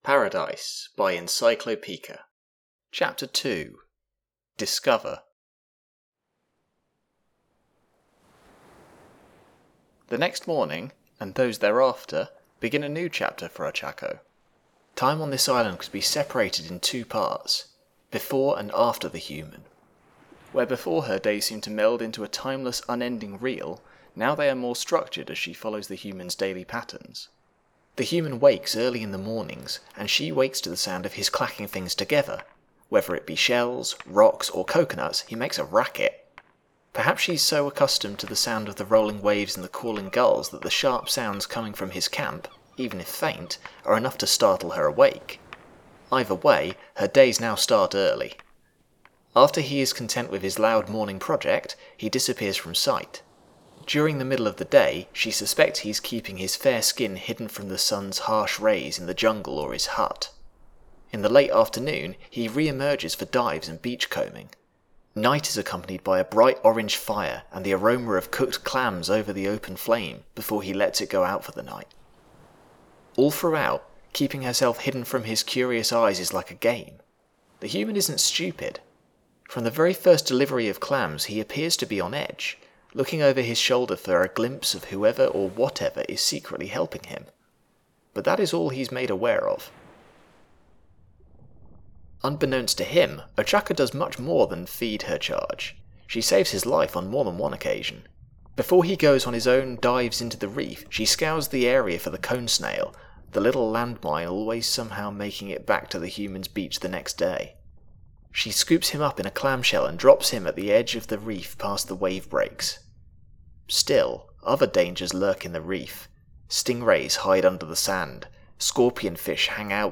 Mermaid AU Drama Romance
Voice of Izuku Midoriya
Voice of Ochako Uraraka
" underwater ambience "
" Underwater / Breathing " by ryanconway This sound is licensed under CC BY 3.0 . " Heavy Rain " by lebaston100 This sound is licensed under CC BY 3.0 . " Thunder, Very Close, Rain, A.wav " by InspectorJ This sound is licensed under CC BY 3.0 .